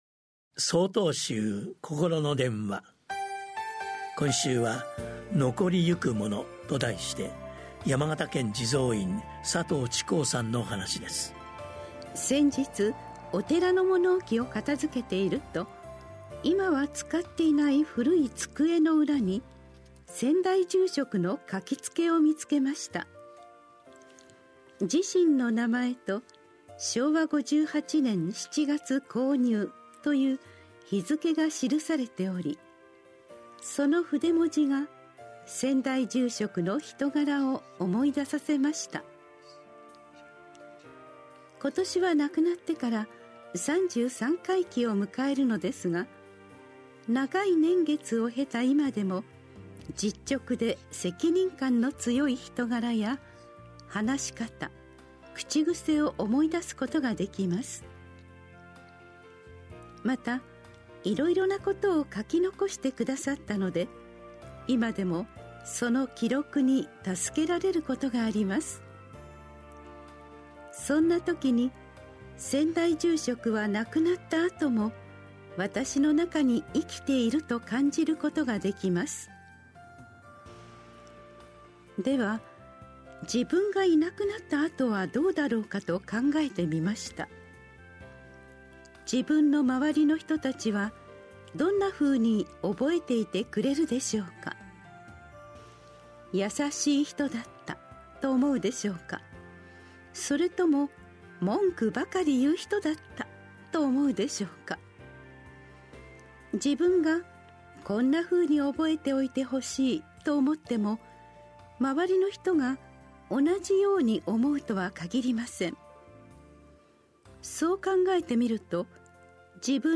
心の電話（テレホン法話）２/４公開『残りゆくもの』 | 曹洞宗 曹洞禅ネット SOTOZEN-NET 公式ページ